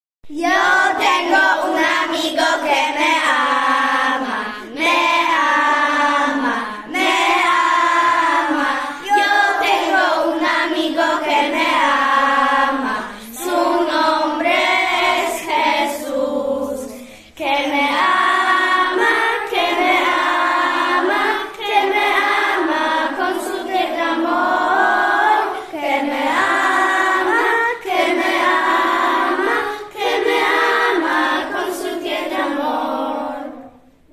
Wcześniej każda klasa przygotowała plakat o jednym z krajów z kontynentu, który jej został wyznaczony, a niektóre klasy nauczyły się śpiewać piosenki w języku w jakich mówi się na danym kontynencie czy też w kraju, o którym przygotowywały plakat.